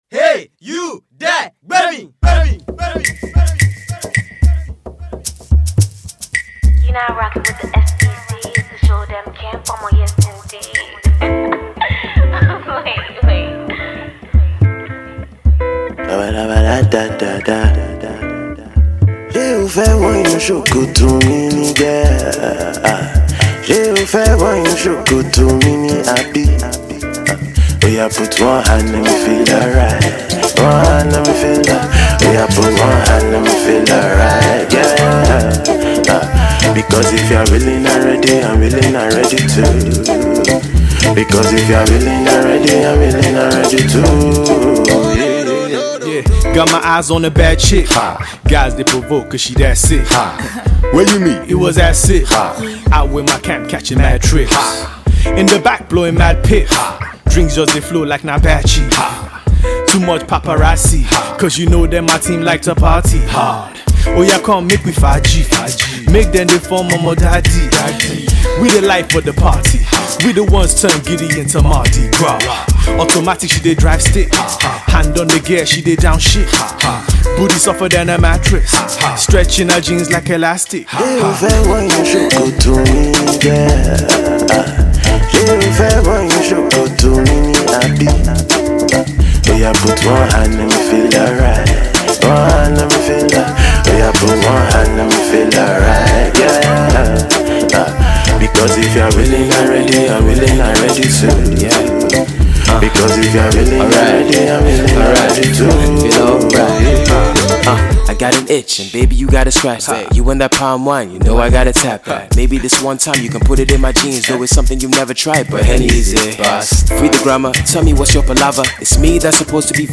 Nigerian Hip-Hop, then they got the husky crooner